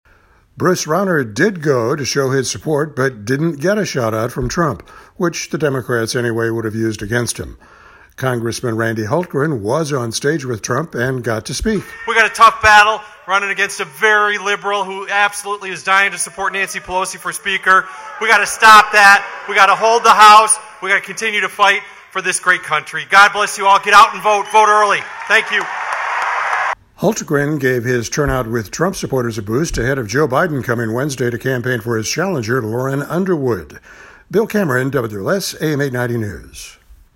Congresssman Randy Hultgren WAS on stage with Trump and got to speak.